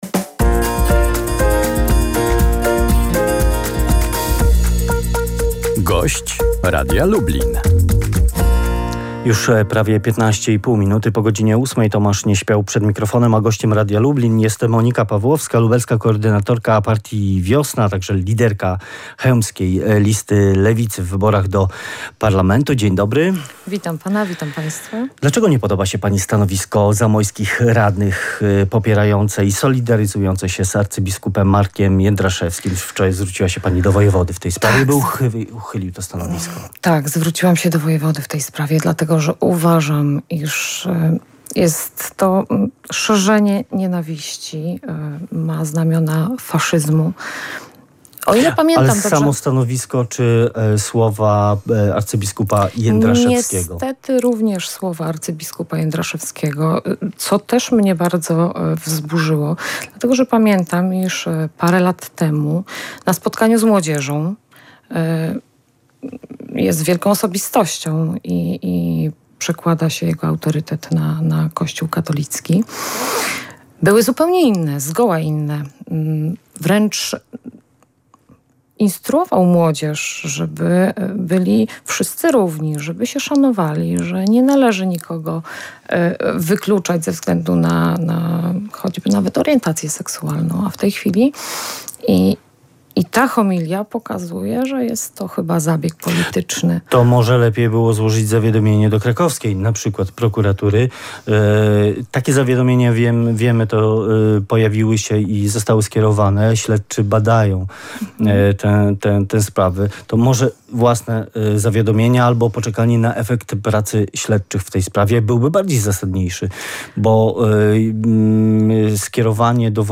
Monika Pawłowska na antenie Radia Lublin poinformowała, że szczegółowe propozycje programowe zostaną zaprezentowane podczas wrześniowej konwencji.